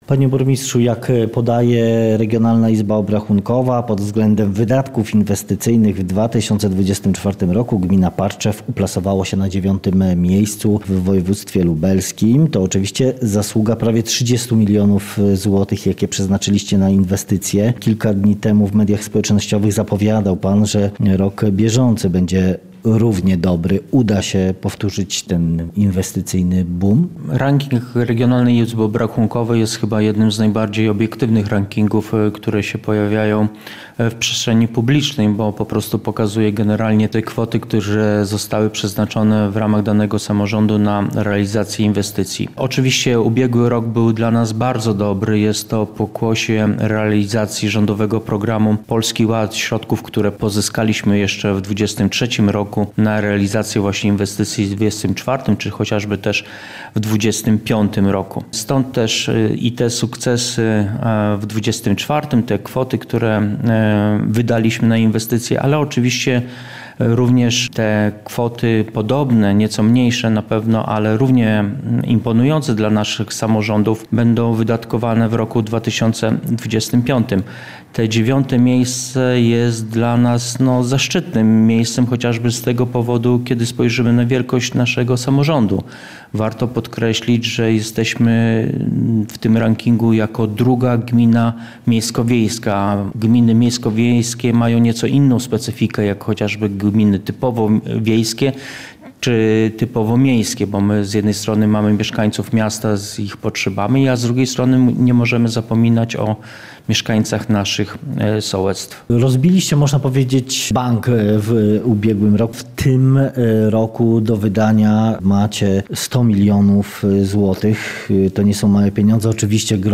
Rozmowa z burmistrzem Parczewa Pawłem Kędrackim